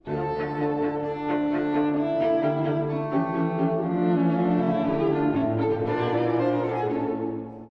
第1楽章 気品と冷徹さが同居する冒頭
(第一主題) 古い音源なので聴きづらいかもしれません！
「ラ・レ・ミ・ラ」から始まる、印象的なモチーフが曲全体を支配します。
まるでバロック音楽のような構造美を持ちます。
また、1stVnが歌う旋律が、全体の響きに丸みを持たせています。